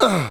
VOX SHORTS-1 0010.wav